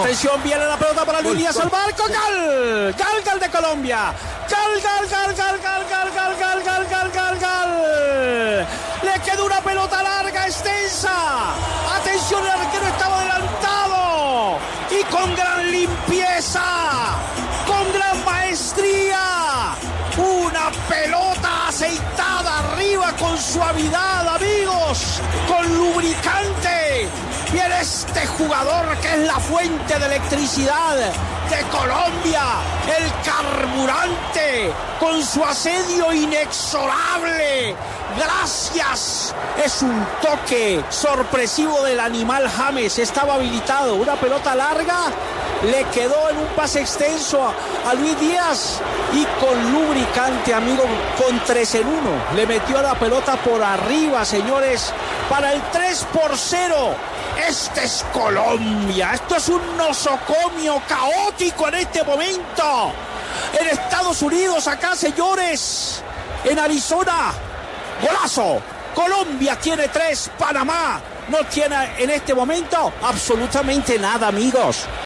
“Una pelota aceitada con suavidad”: narración de Martín de Francisco al gol de Lucho Díaz
Martín De Francisco explotó de alegría con el tercer gol de la selección Colombia.
El encuentro disputado en el estadio de la Universidad de Phoenix, se vistió de amarillo en su gran mayoría para apoyar a Colombia, tuvo la imperdible narración de Martín de Francisco con el gol de Luis Díaz que significa el 3-0 parcial.